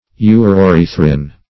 Search Result for " uroerythrin" : The Collaborative International Dictionary of English v.0.48: Uroerythrin \U`ro*e*ryth"rin\, n. [See 1st Uro- , and Erythrin .]